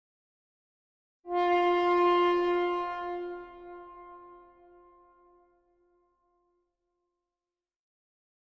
Tuba Tone 2 - Single, Higher